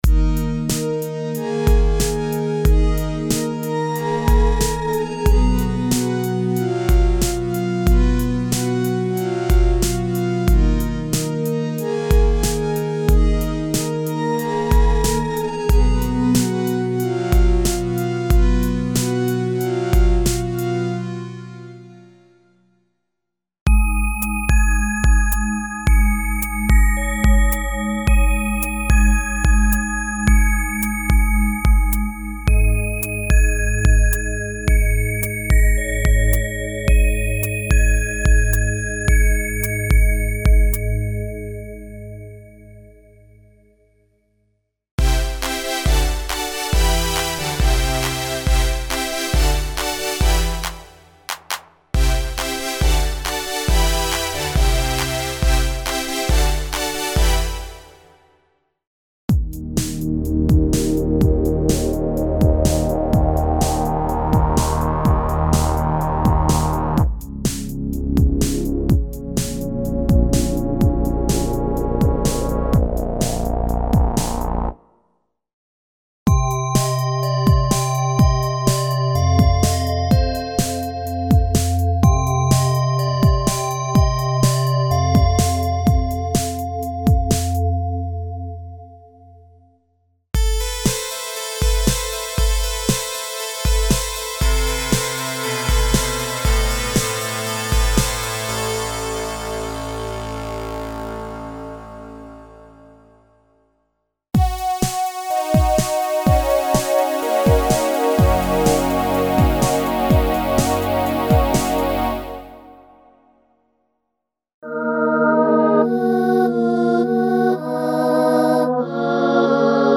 Emulations of vintage digital synthesizers - PPG, DX (FM synthesis) program variations (experimental and "ice-cold" digital pads).
Info: All original K:Works sound programs use internal Kurzweil K2500 ROM samples exclusively, there are no external samples used.
K-Works - Digital Volume 1 - LE (Kurzweil K2xxx).mp3